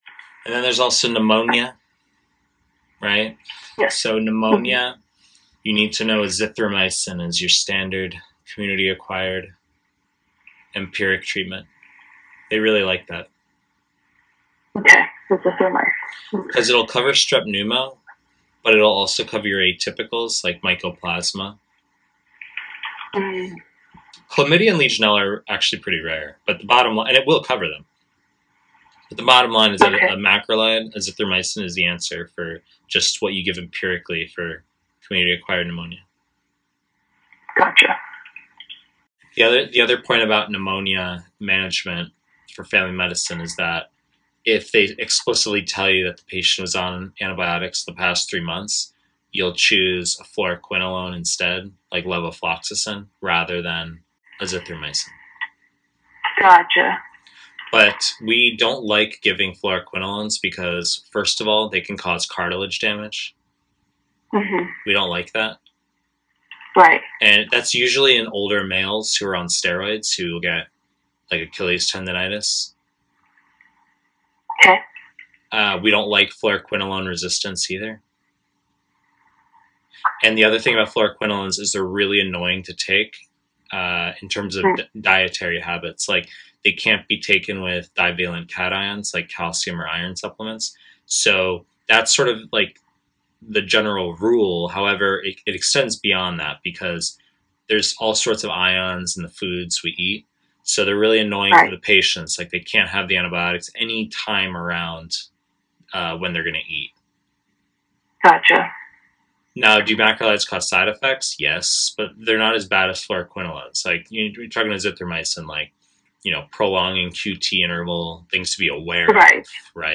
Family medicine / Pre-recorded lectures